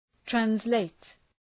Προφορά
{,trænz’leıt, ,træns’leıt}
translate.mp3